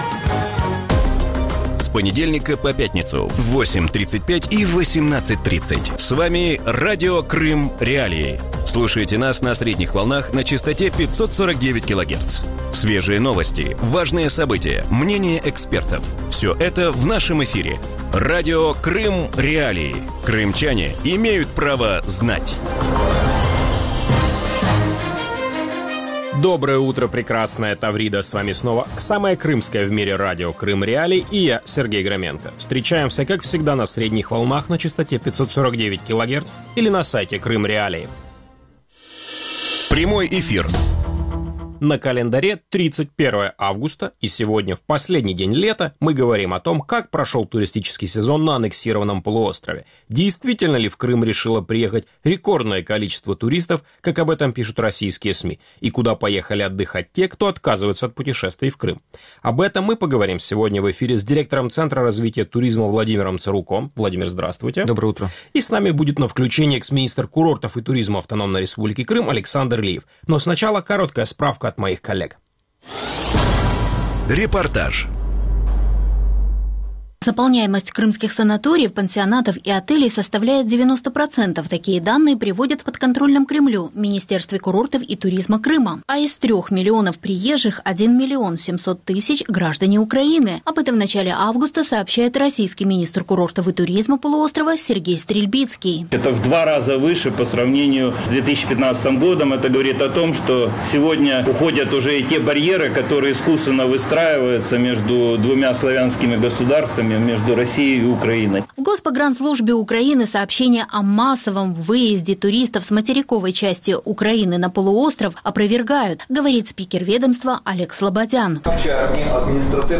Вранці в ефірі Радіо Крим.Реалії говорять про те, як пройшов туристичний сезон на анексованому півострові. Чи дійсно в Крим вирішила приїхати рекордна кількість туристів, як про це пишуть російські ЗМІ? І куди їдуть відпочивати ті, хто відмовляються від подорожей до Криму?